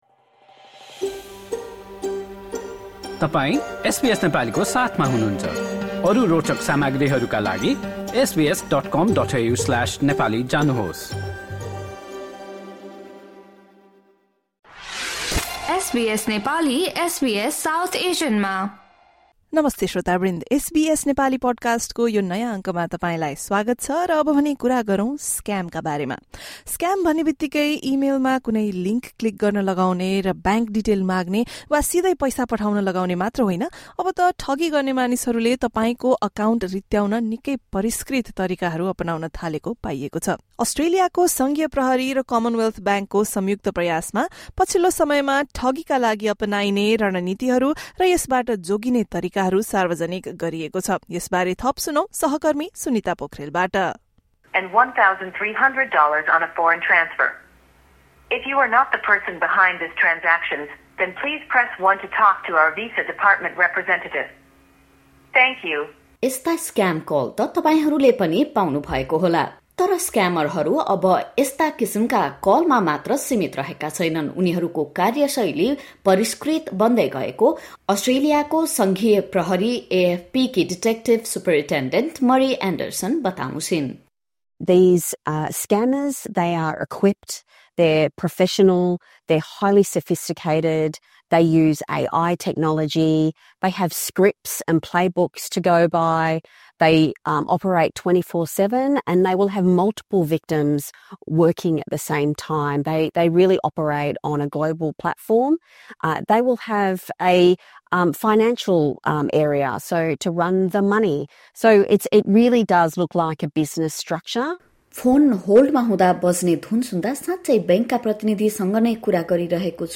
Australians are being warned that scammers posing as saviours are using new and sophisticated techniques to drain money from bank and cryptocurrency accounts. The Australian Federal Police (AFP) and the Commonwealth Bank have outlined the latest tactics being used and how to stay protected. Listen to the full report for more details.